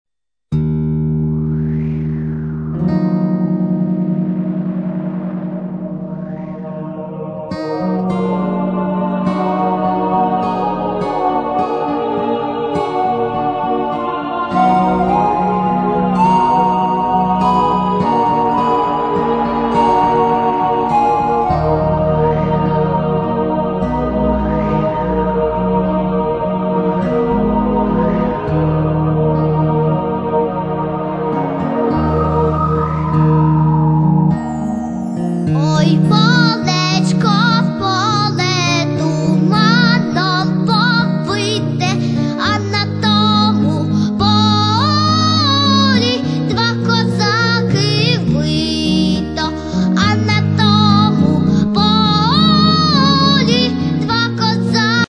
Children Perform Ukrainian Folk And Patriotic Songs